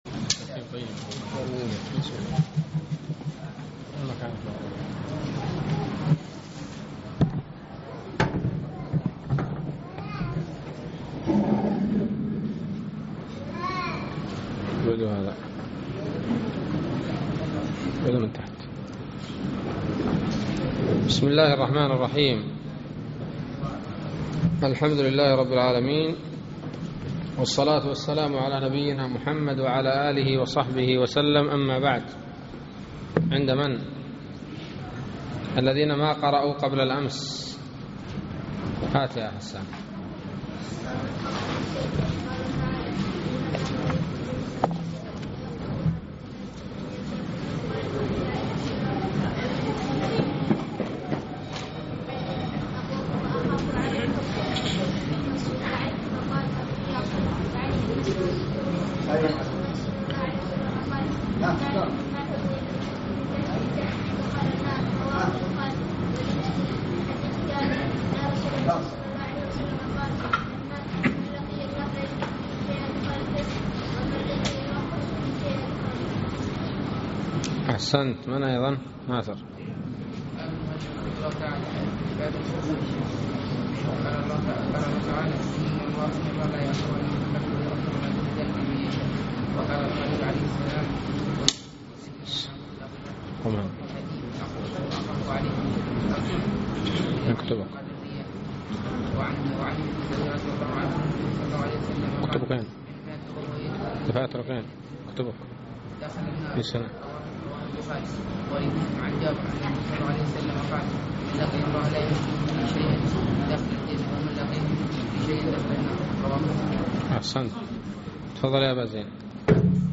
الدرس الثالث عشر من شرح كتاب التوحيد